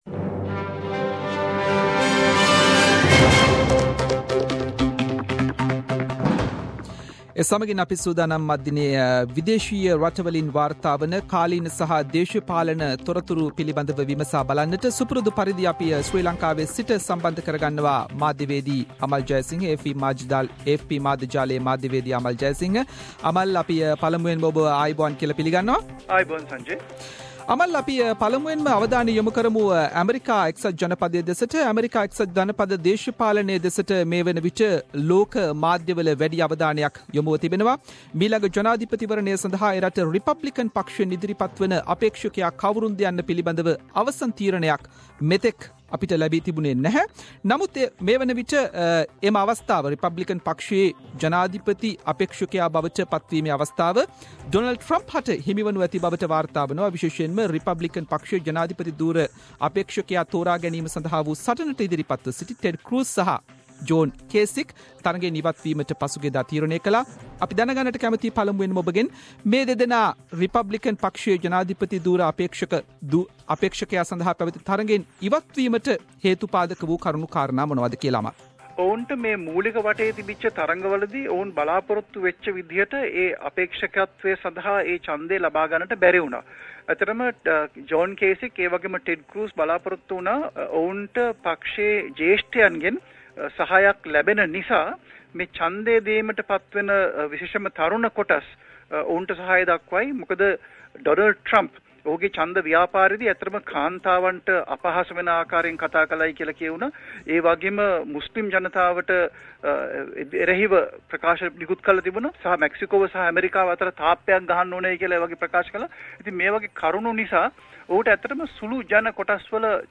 “SBS Sinhala” Around the World – Weekly World News highlights…..